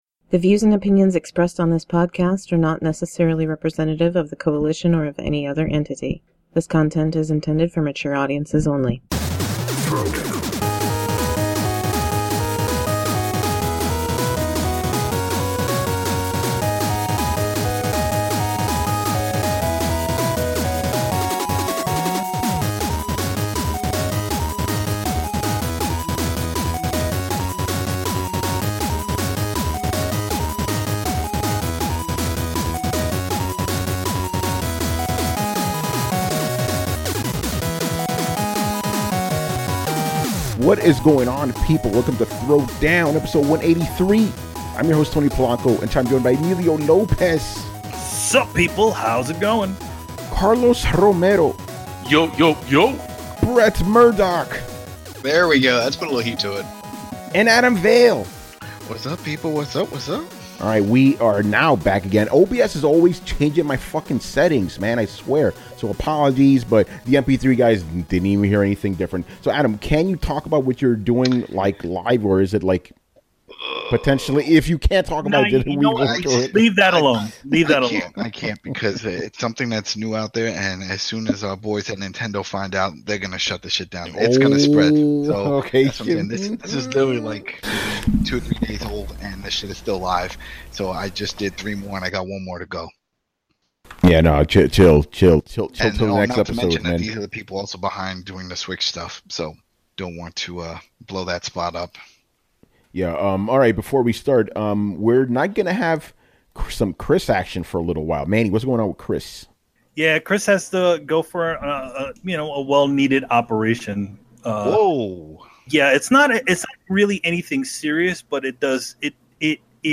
Follow the panelists on Twitter